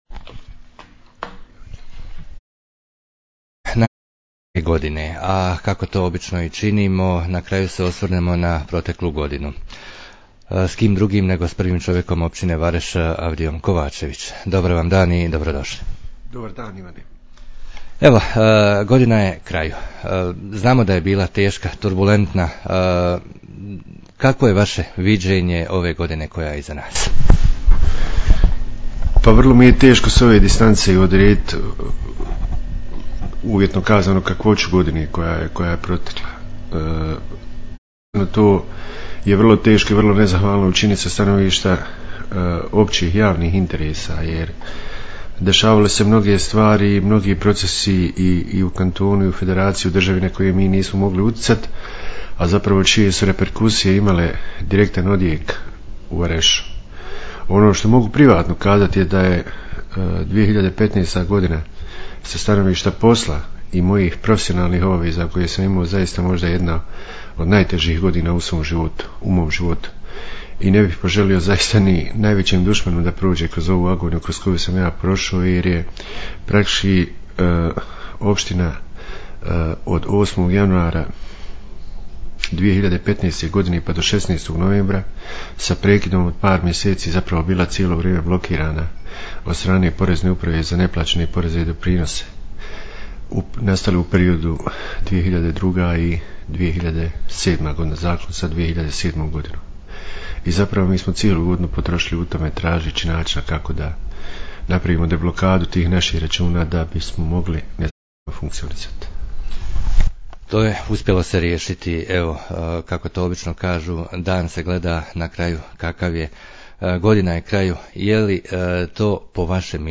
Razgovor na kraju 2015. godine s načelnikom općine